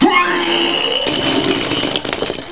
barf.wav